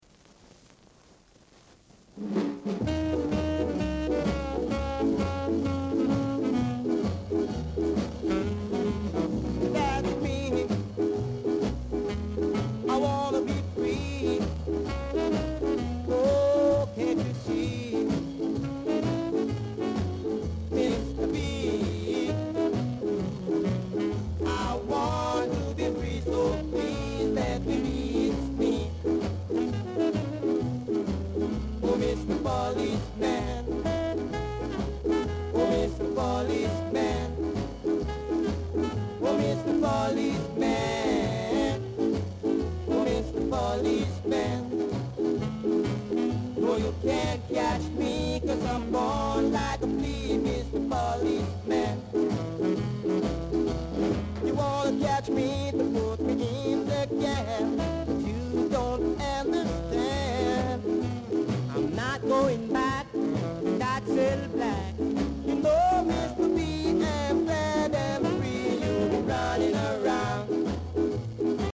Ska Vocal Duo
Rare! great ska vocal w-sider!